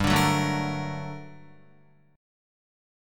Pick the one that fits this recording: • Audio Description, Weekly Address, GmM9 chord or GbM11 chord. GmM9 chord